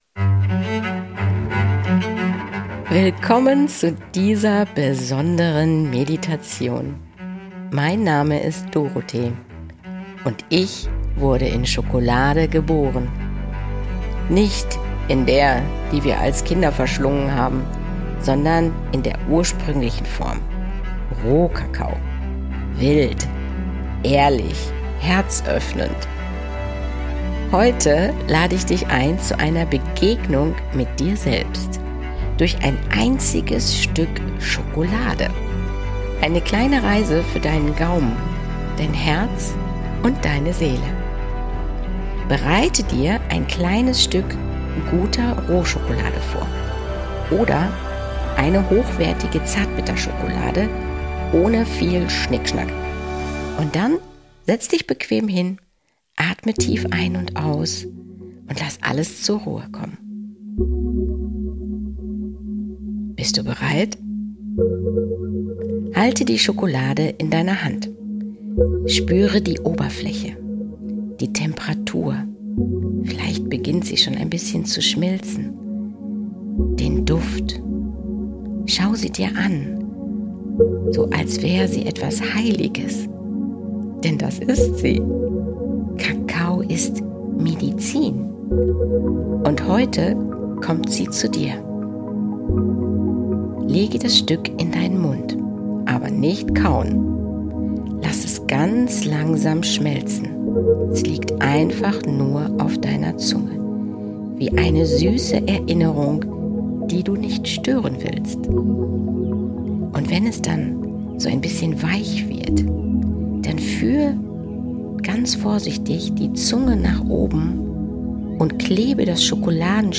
Nr. 18 - Bewusstseinserweiterung mit Schokolade - Eine geführte Schokoladen-Meditation